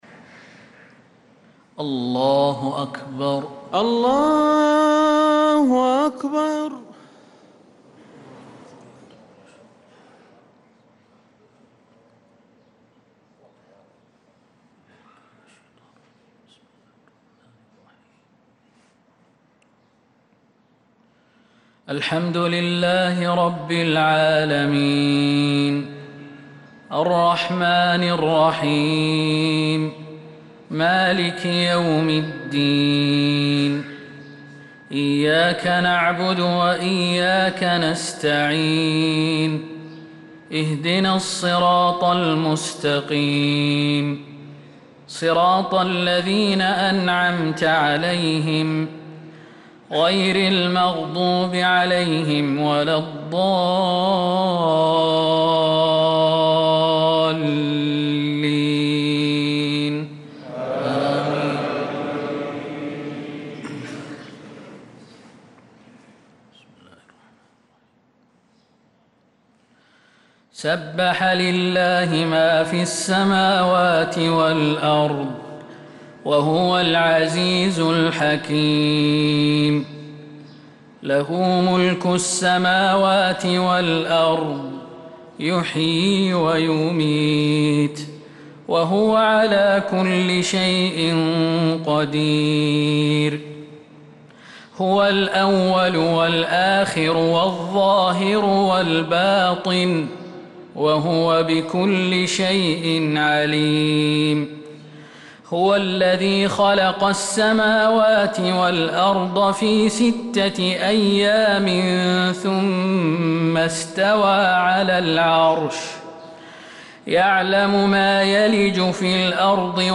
صلاة الفجر للقارئ خالد المهنا 5 ذو الحجة 1445 هـ